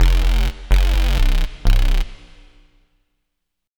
bass01.wav